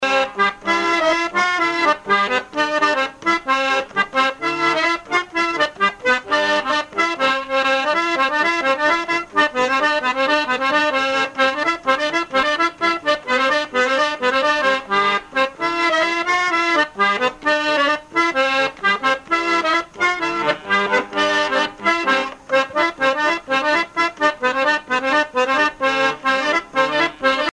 Chants brefs - A danser
musique à danser à l'accordéon diatonique
Pièce musicale inédite